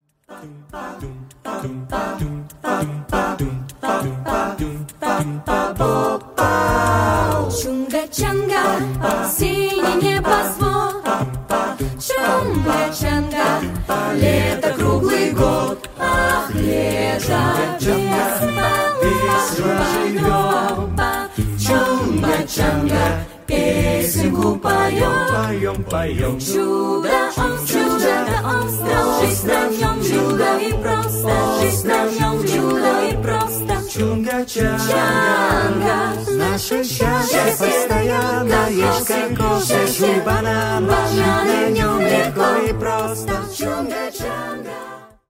А капелла без сопровождения